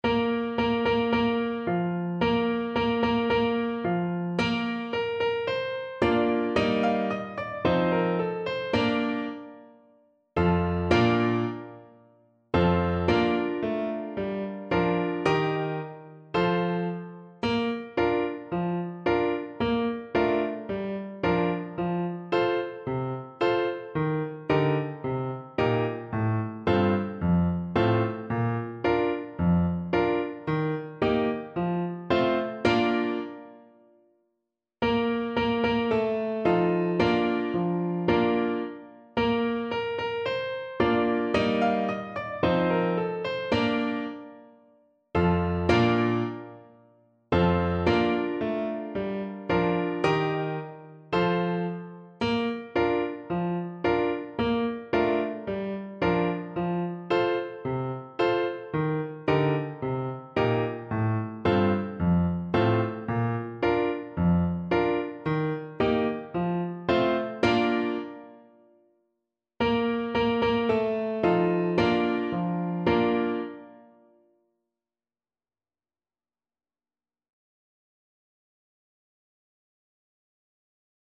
Clarinet version
2/2 (View more 2/2 Music)
Two in a bar =c.80